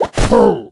hunter_deploy_03.ogg